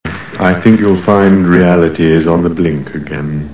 Marvin (from The Hitchhiker's Guide to the Galaxy) on reality.